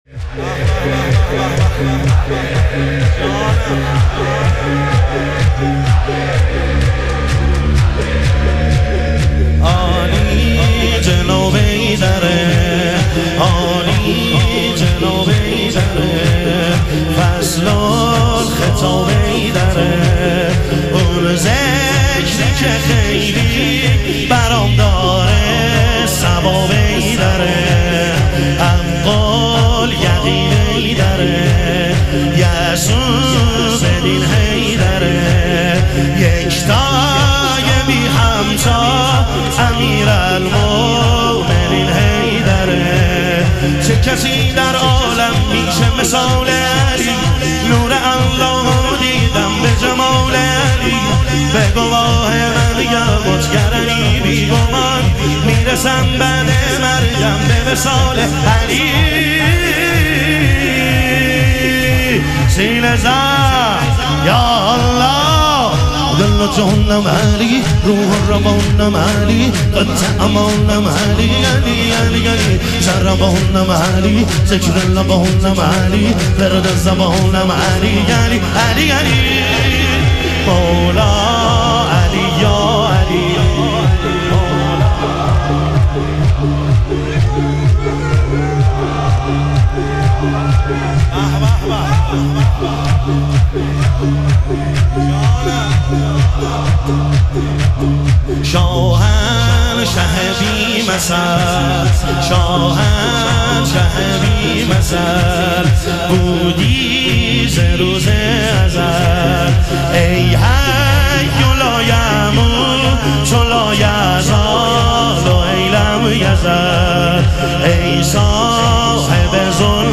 اربعین امیرالمومنین علیه السلام - شور - 4 - 1404